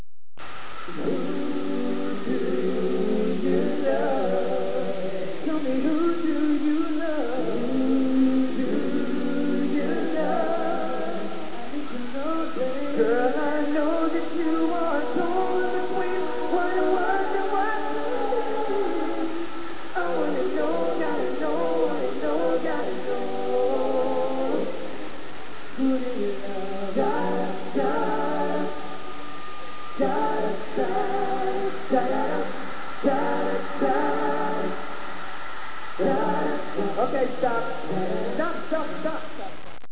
performed live